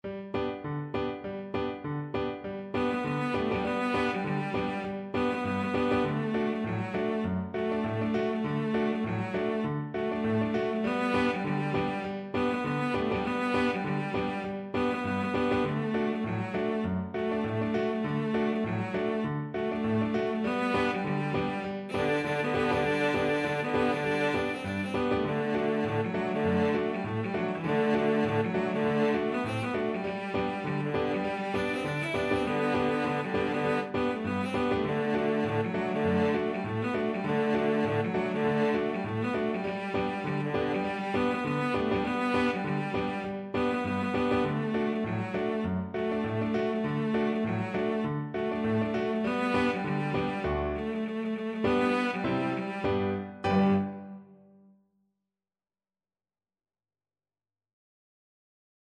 Cello
G major (Sounding Pitch) (View more G major Music for Cello )
Two in a bar with a light swing =c.100
Traditional (View more Traditional Cello Music)